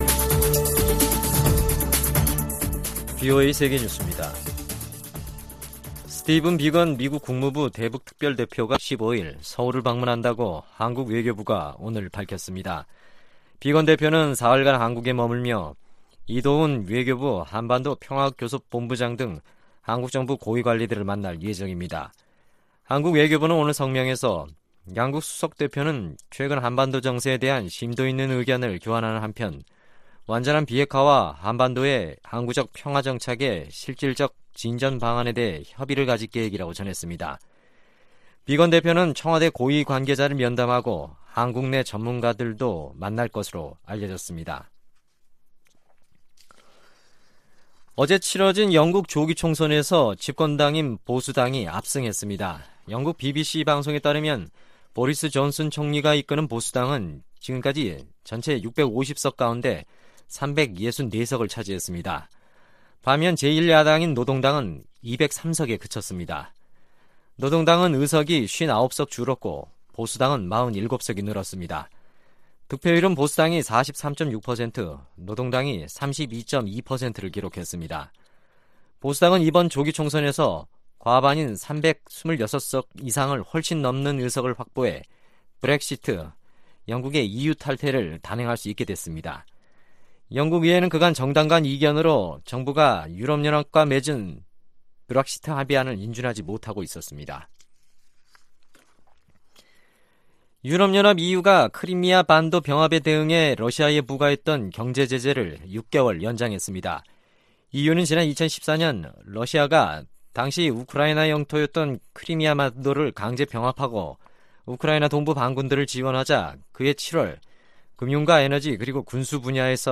VOA 한국어 간판 뉴스 프로그램 '뉴스 투데이', 2019년 12월 13일 3부 방송입니다. 북한의 “유감스럽고 무분별한 행동”은 더 이상 용납할 수 없다고 미 국무부 동아태 차관보가 말했습니다. 미국 국방부는 최근 잇따른 북한의 ‘위협적인’수사를 진지하게 받아들이고 있다고 밝혔습니다.